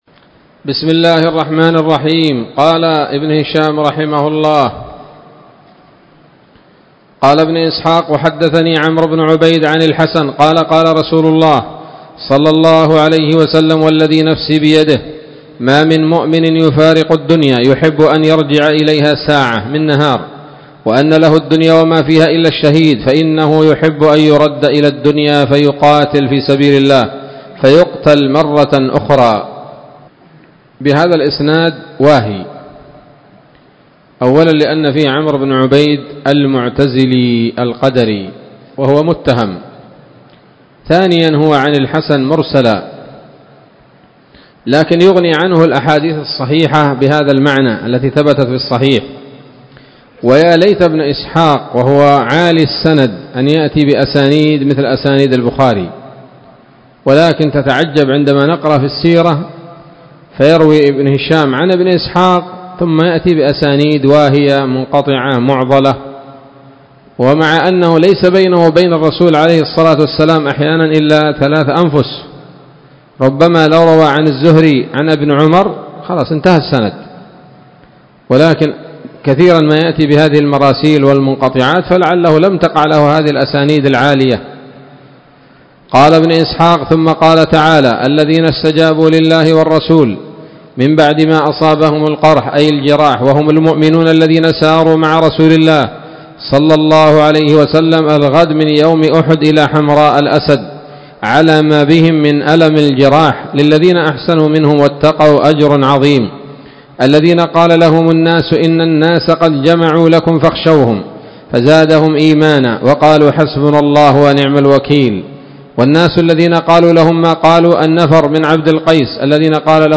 الدرس الرابع والسبعون بعد المائة من التعليق على كتاب السيرة النبوية لابن هشام